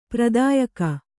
♪ pradāyaka